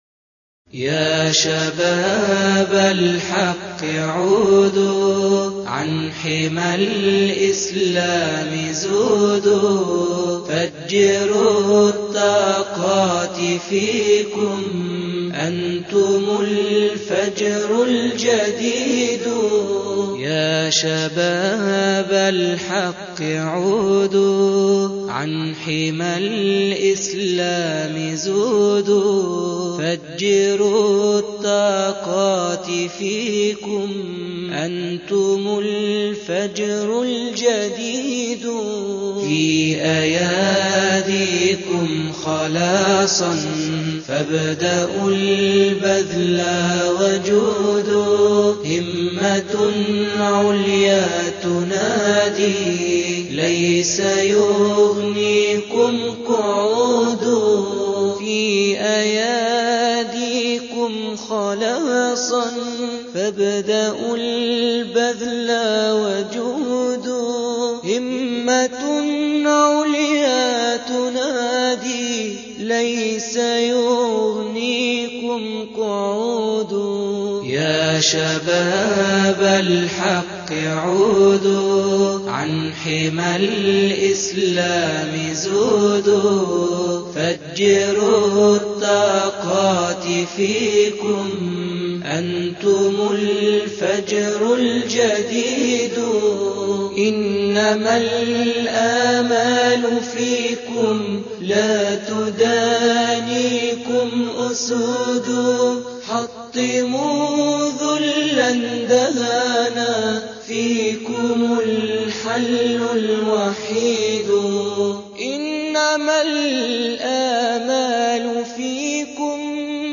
تحديث الصفحة نشيد " ياشباب الاسلام " جميل جداً هيعجبكم